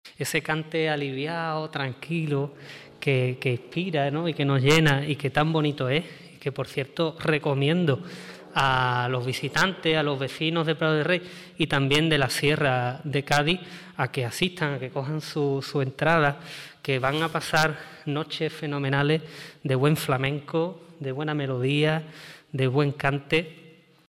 Serranas_diputado-de-Cultura.mp3